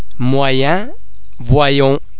oi oê oe oy [Wa][ typically French sound]
wa_moyen.mp3